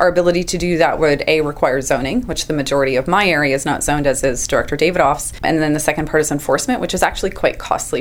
Area D Director Aimee Watson says enforcing seasonal use isn’t easy.